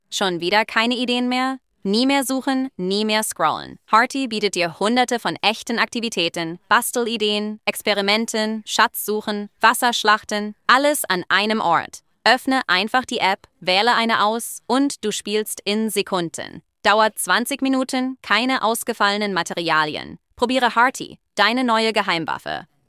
dubbed-de.mp3